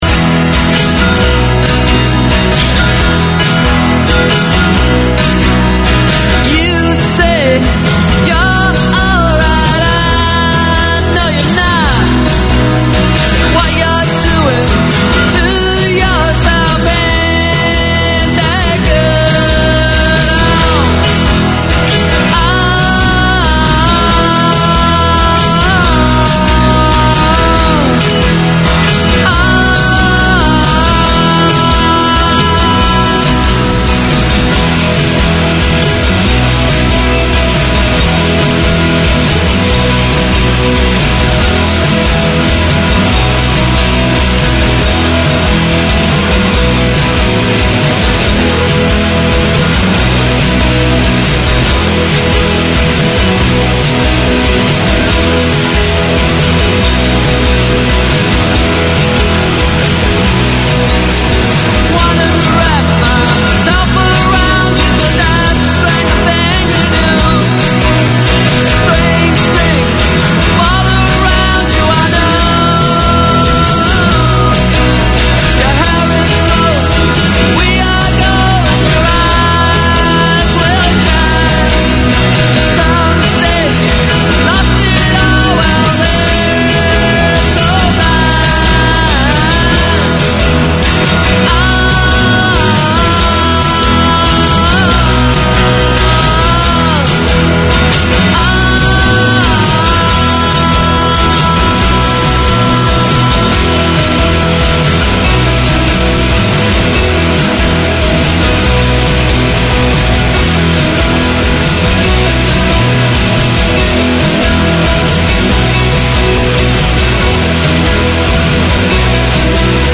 Unfiltered Conversations: Chai & Chat, is a safe and open space where young South Asian girls, can openly discuss, debate, and ask questions about relationships, culture, identity, and everything in between. They are on-air to break the stigma, share experiences, and support each other through honest conversations over a cup of chai.